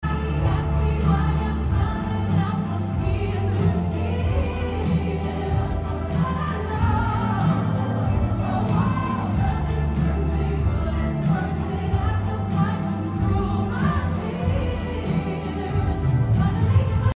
63,000 souls united by one queen and one song